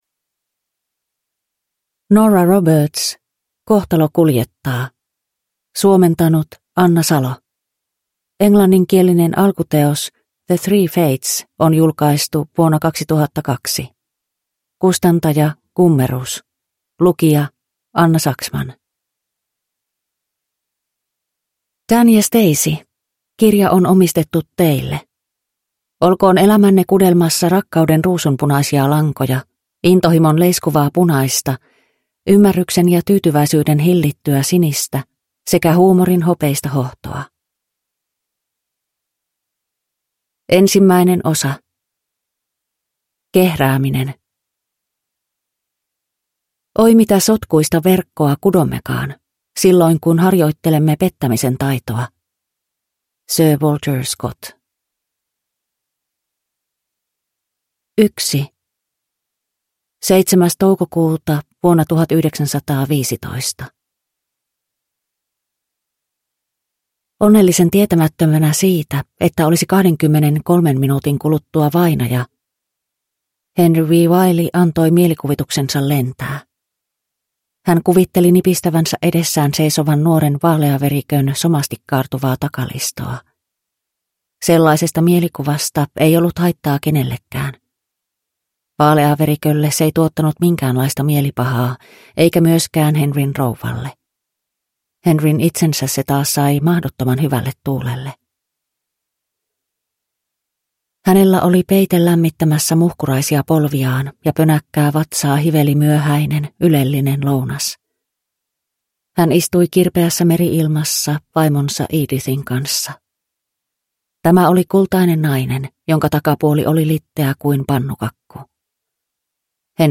Kohtalo kuljettaa – Ljudbok – Laddas ner